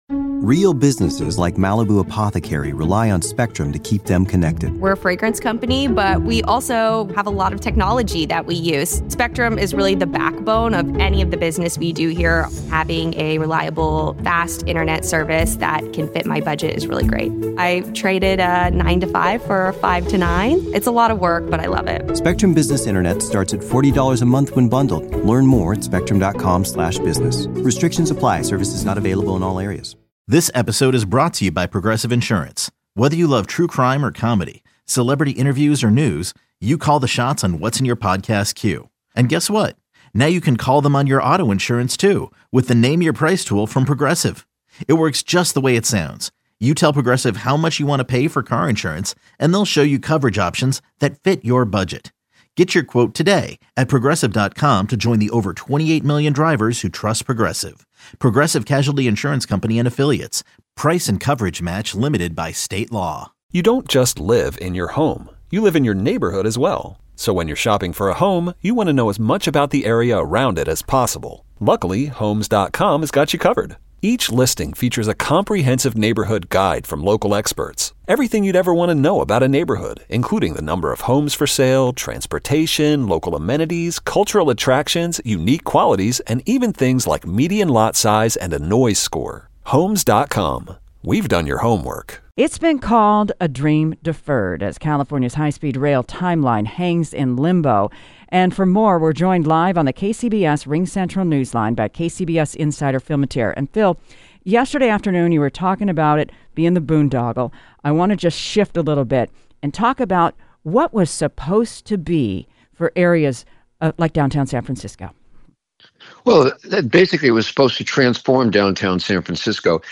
Breaking news and analysis from around the Bay Area, the state capitol and beyond.